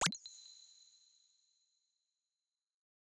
generic-hover-soft.wav